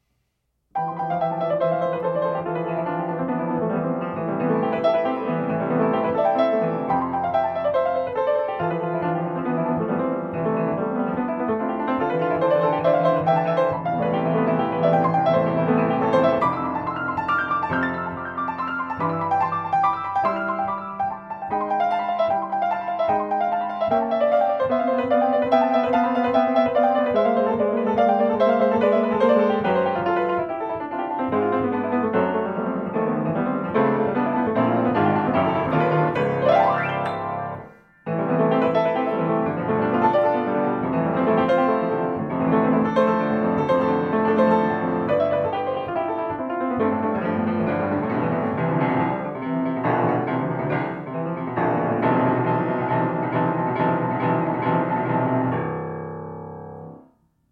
“Etude (RH 4-5)”
Had to record this one before my right elbow fell off.Â Ravel quote for music geeks.